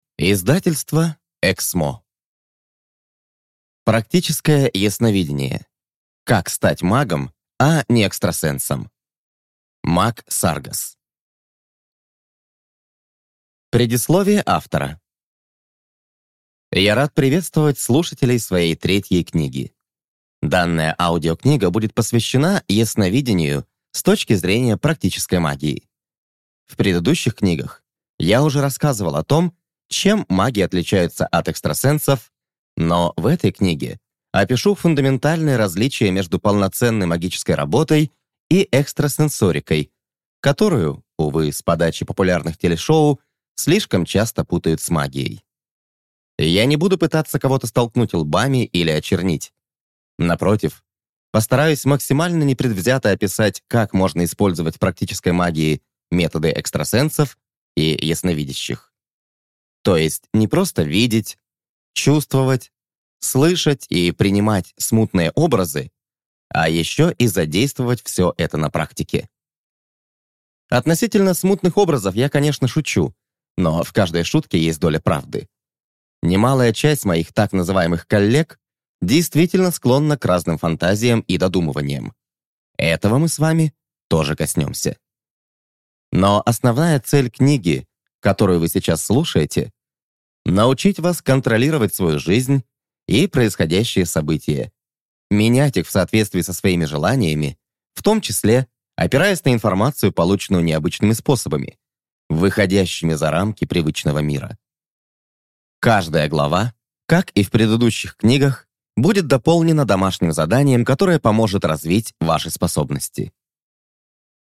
Аудиокнига Практическое ясновидение. Как стать магом, а не экстрасенсом | Библиотека аудиокниг